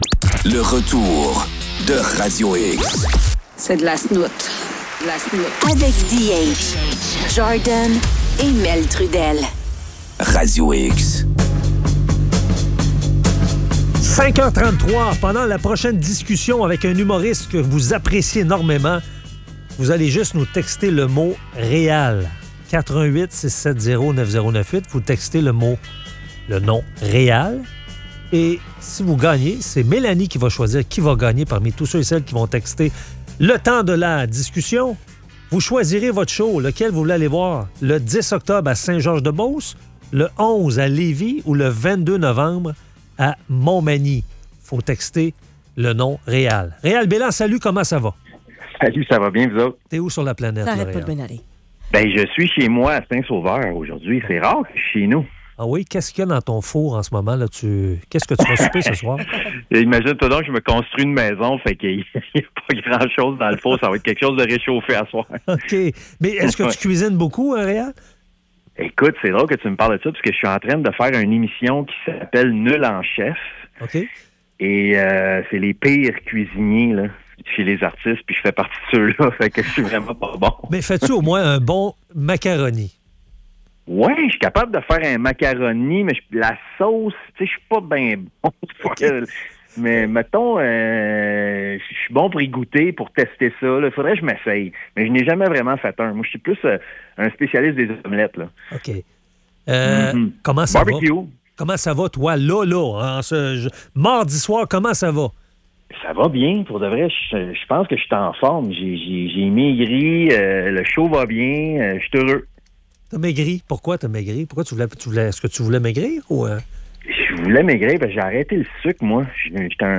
Entrevue avec Réal Béland.